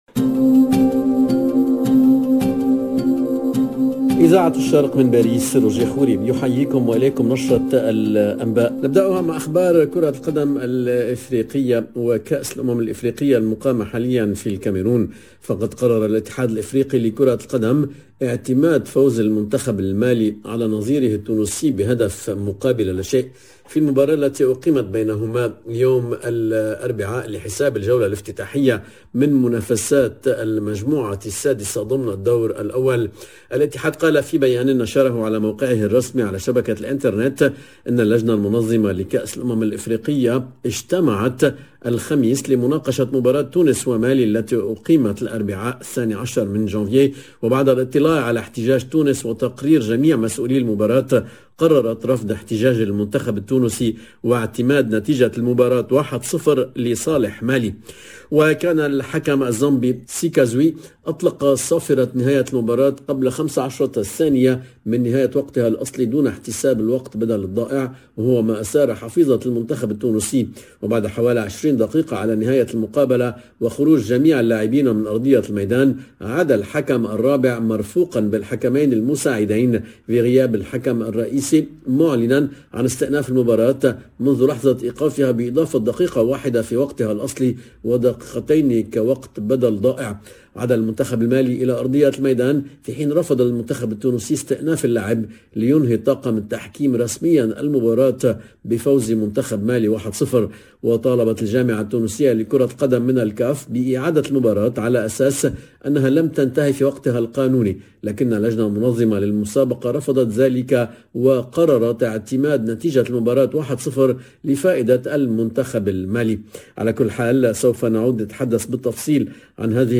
Maroc Tunisie Comores Mali CAN Cameroun football covid 14 janvier 2022 - 15 min 13 sec LE JOURNAL DE LA MI-JOURNEE EN LANGUE ARABE DU 14/01/22 LB JOURNAL EN LANGUE ARABE العناوين الاتحاد الافريقي لكرة القدم يقرر اعتماد فوز المنتخب المالي على نظيره التونسي واحد -صفر بعد احتجاج تونس على التحكيم ….